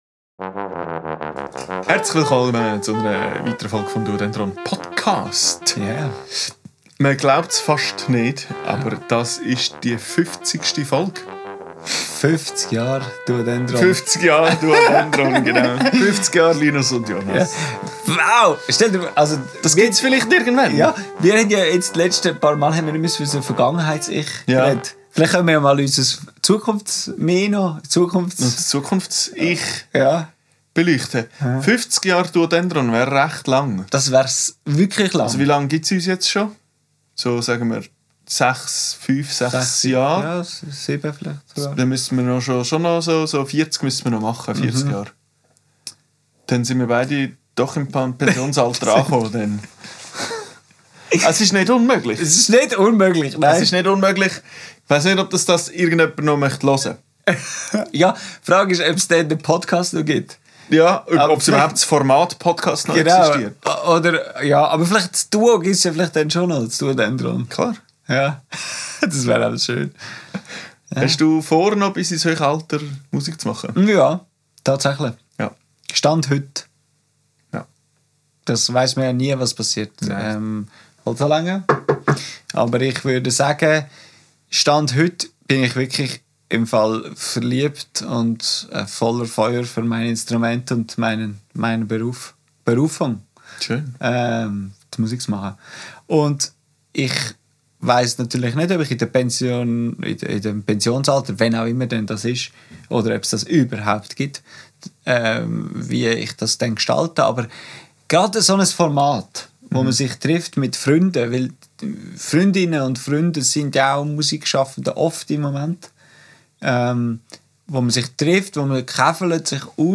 im Atelier